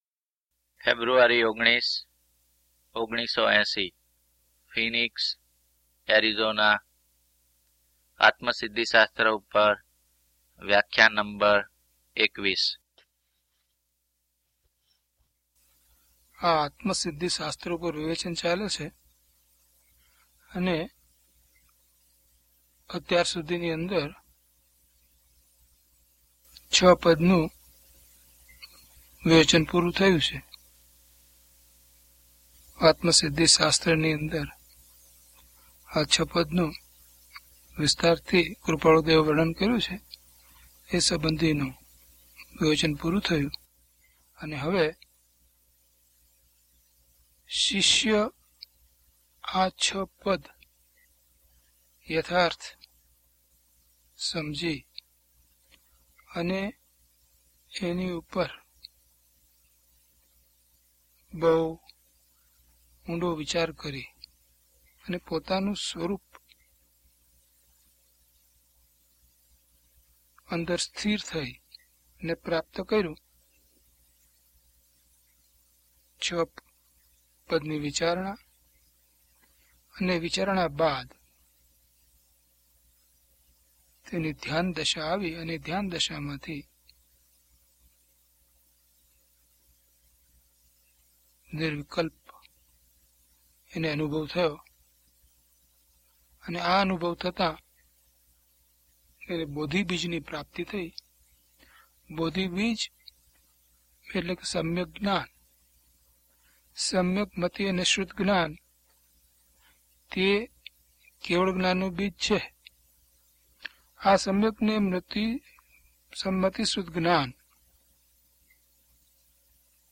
DHP030 Atmasiddhi Vivechan 21 - Pravachan.mp3